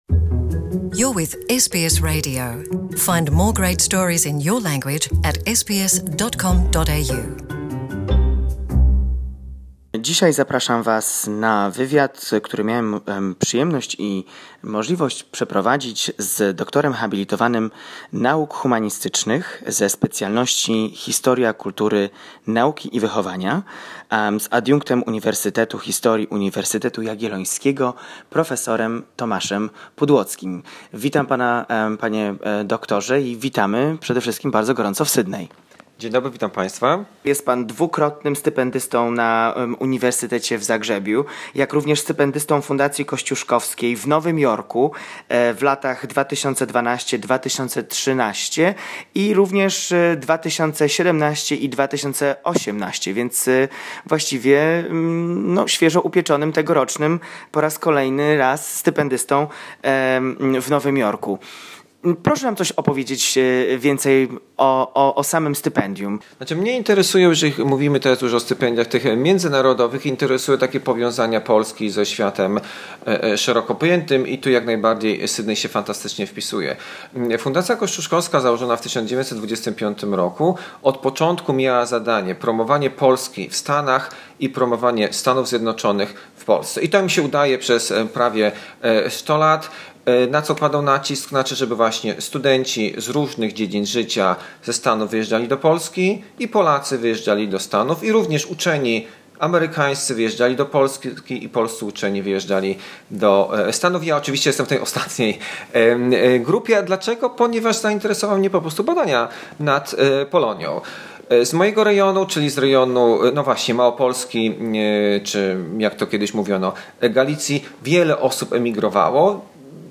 in a conversation